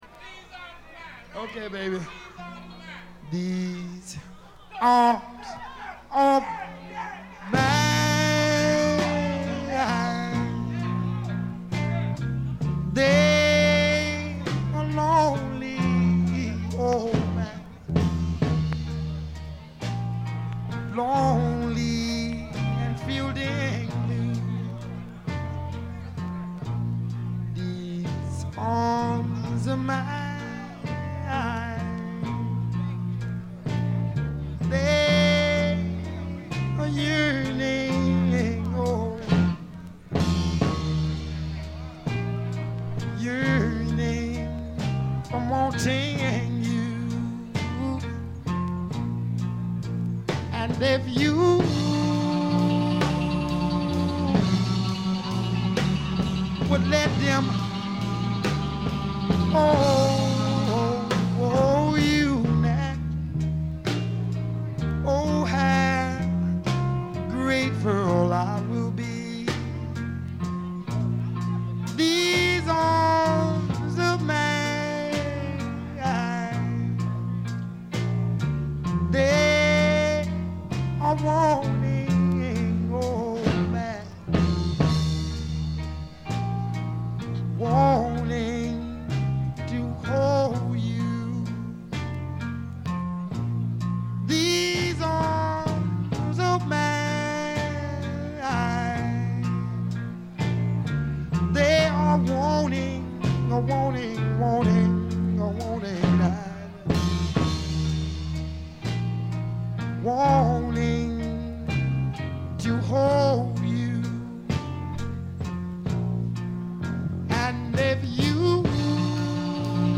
散発的なプツ音が少々。
試聴曲は現品からの取り込み音源です。